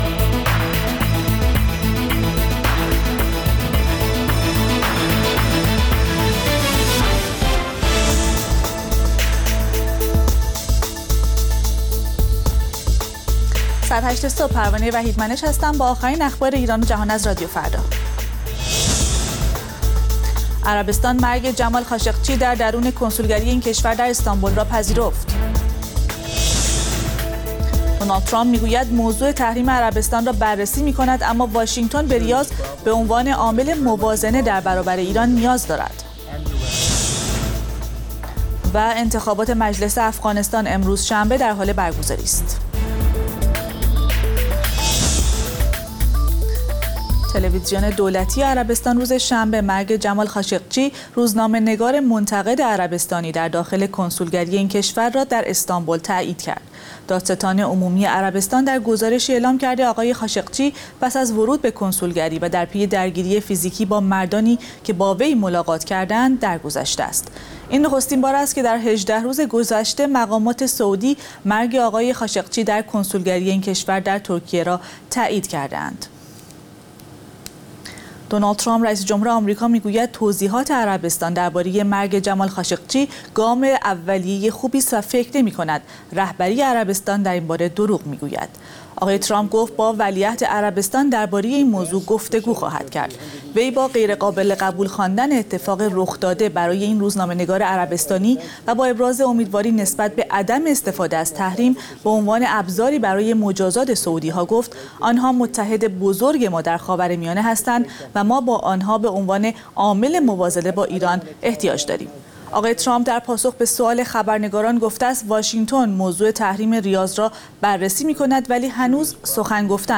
اخبار رادیو فردا، ساعت ۸:۰۰